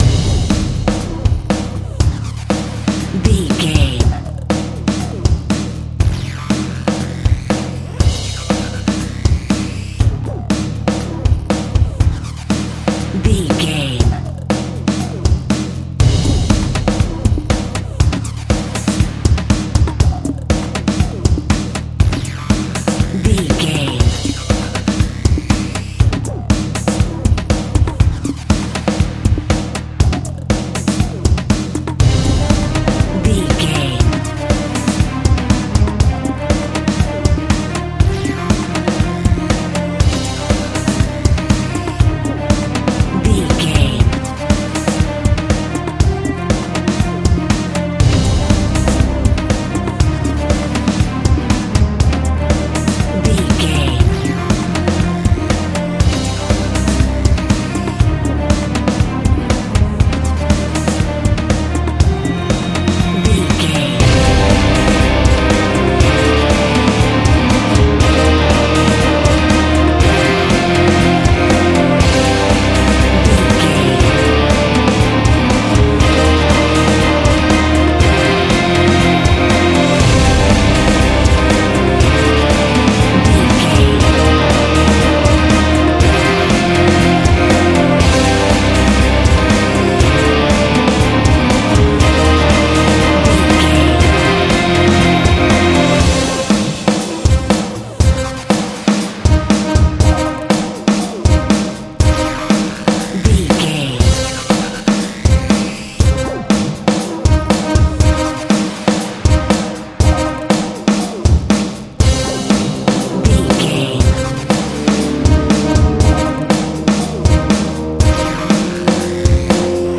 Chase Horror Music.
Aeolian/Minor
tension
ominous
eerie
drums
violin
cello
double bass
synthesiser
brass
staccato strings
staccato brass
french horn
taiko drums
glitched percussion